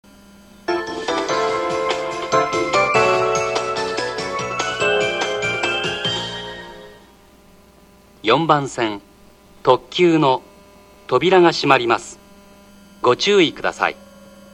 4番線特急発車放送　　4番線普通発車放送